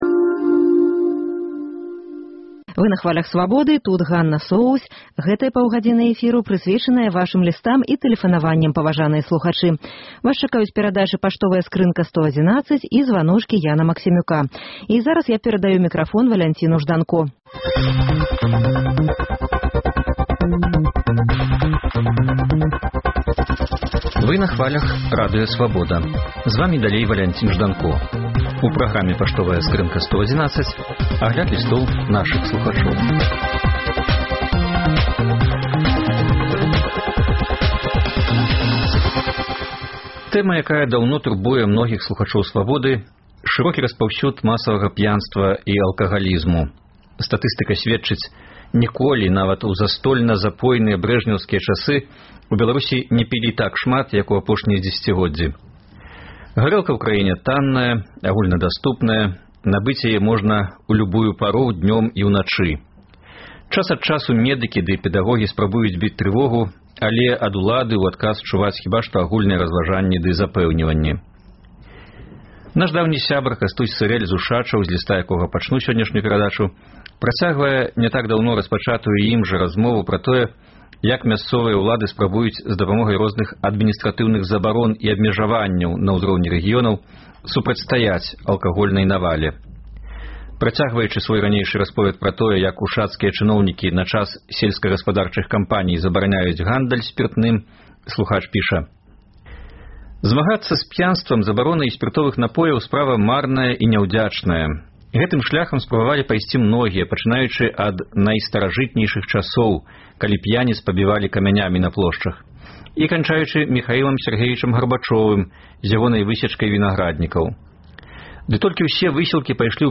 Гэты ды іншыя лісты слухачоў Свабоды чытае і камэнтуе